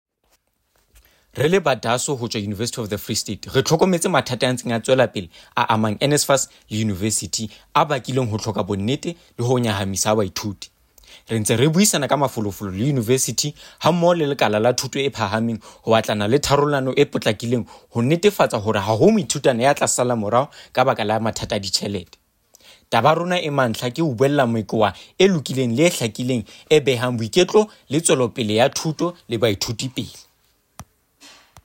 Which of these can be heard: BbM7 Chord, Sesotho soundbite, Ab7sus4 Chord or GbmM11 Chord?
Sesotho soundbite